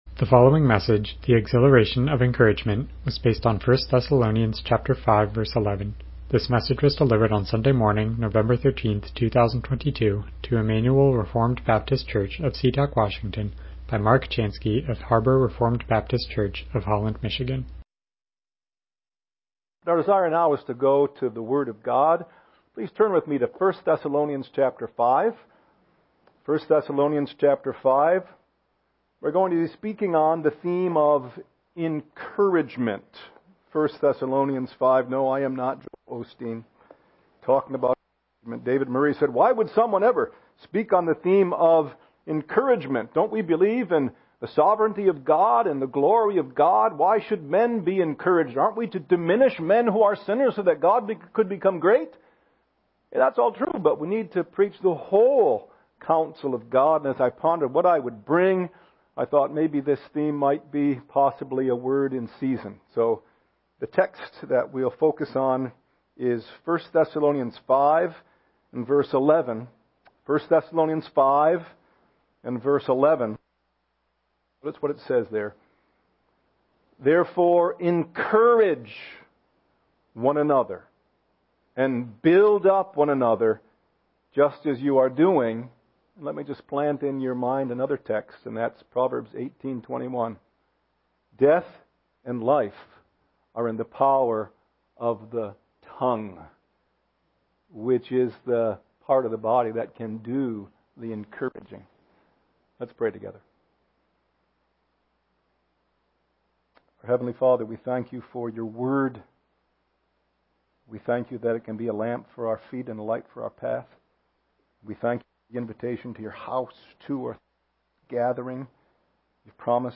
Passage: 1 Thessalonians 5:11 Service Type: Morning Worship « The Saints